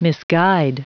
Prononciation du mot misguide en anglais (fichier audio)
Prononciation du mot : misguide